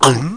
1 channel
00411_Sound_GARgloup.mp3